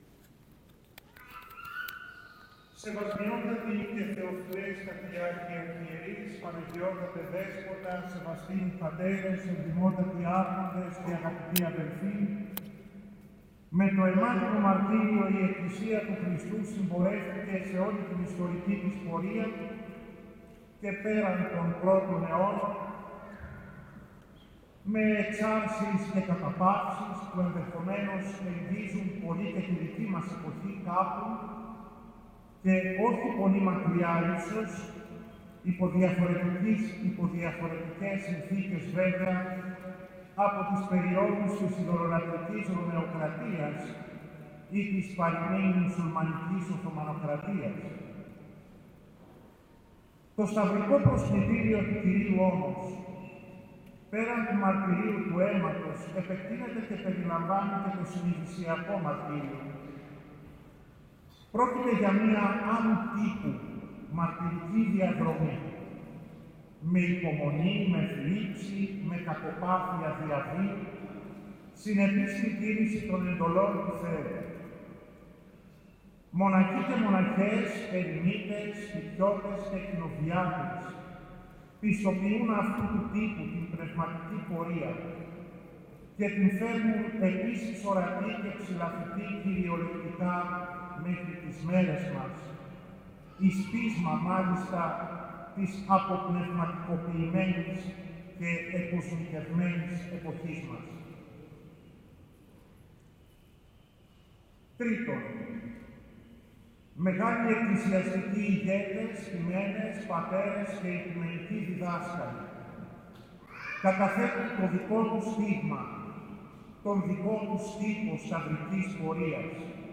Το πρωί της Κυριακής 15ης Σεπτεμβρίου 2024 τελέσθηκε η ακολουθία του Όρθρου και πολυαρχιερατική Θεία Λειτουργία προεξάρχοντος του εορτάζοντος Μητροπολίτου Θεσσαλονίκης κ. Φιλοθέου.
Τους Αρχιερείς πλαισίωσε πλειάδα ιερέων και διακόνων.